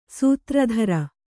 ♪ sūtra dhara